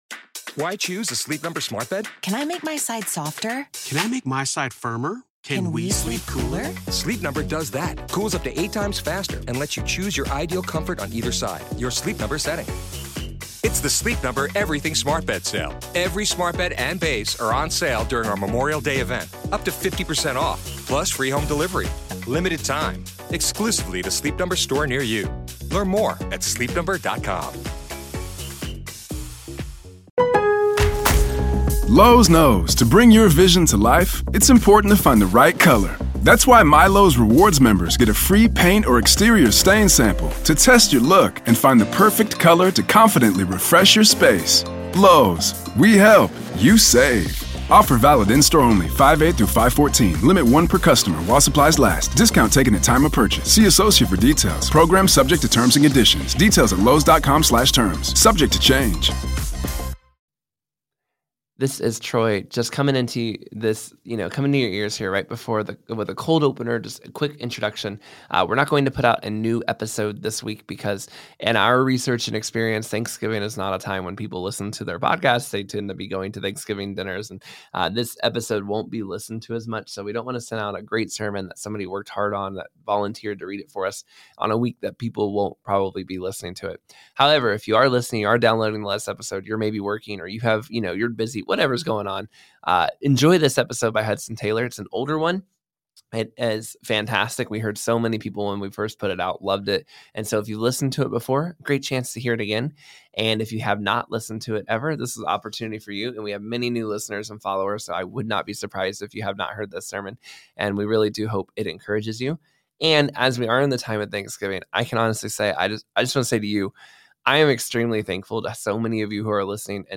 Listen to his early days in traveling to Shanghai and living in Shanghai during a massive civil war. Then listen to his sermon on "Abiding in Christ."